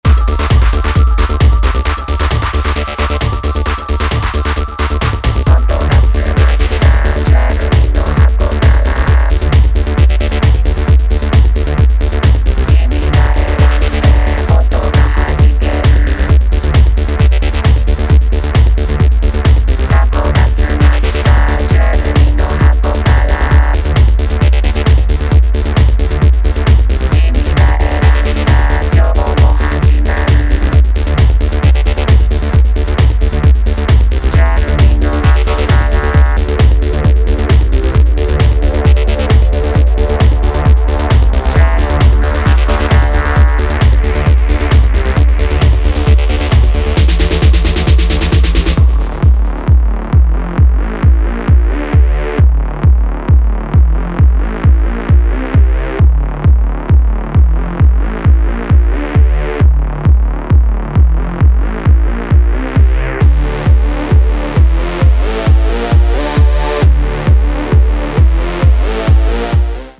electro-pop
a more dancey collection of blips and beats